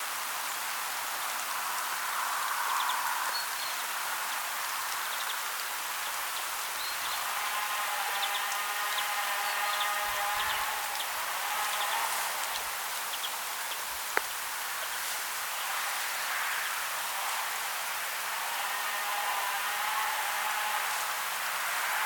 Since I started experimenting with sound in december, I have recorded 3 birdspecies flying over me that I was uncertain of while standing in the garden.
I haven't seen a Greenfinch in a long time, so I am happy that I recorded it flying over.